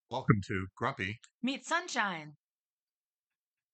Here is a rough cut of what we sound like! https